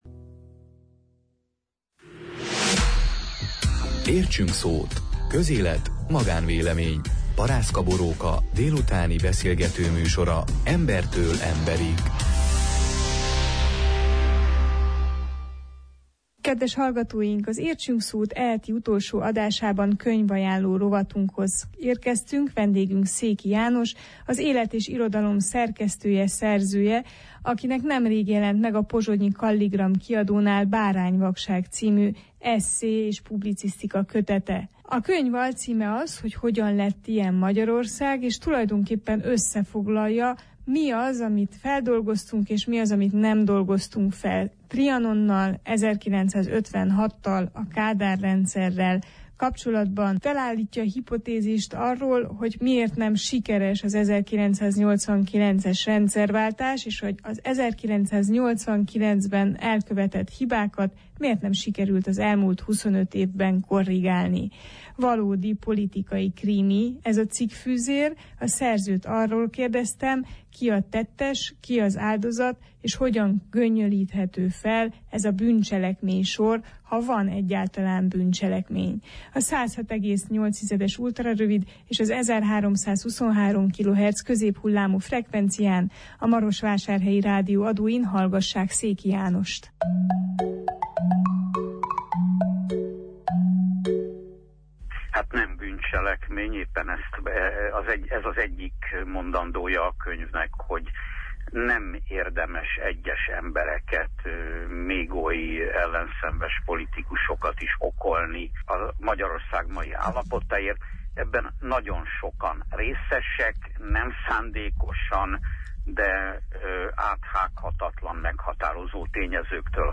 Mi a politikai válság természete, és van-e ebből kivezető út? Van-e értelme a politikai bűnbakkeresésnek? Erről beszélgettünk